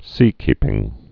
(sēkēpĭng)